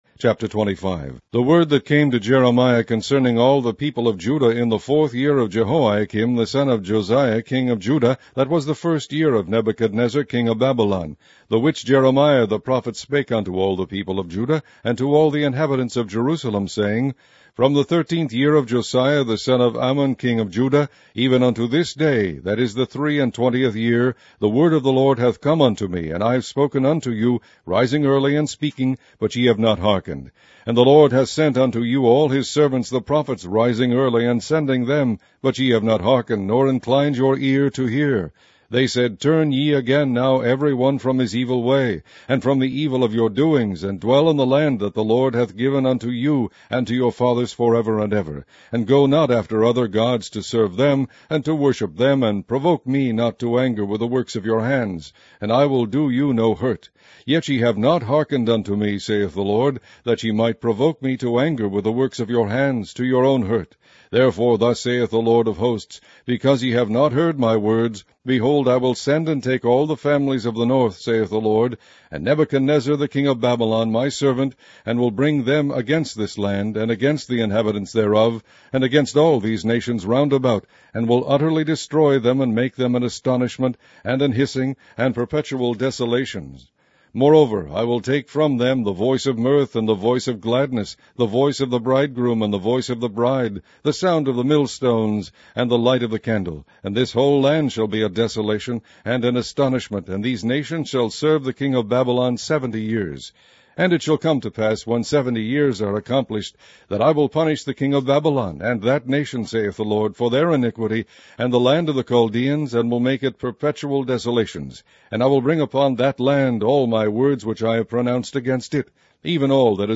Online Audio Bible - King James Version - Jeremiah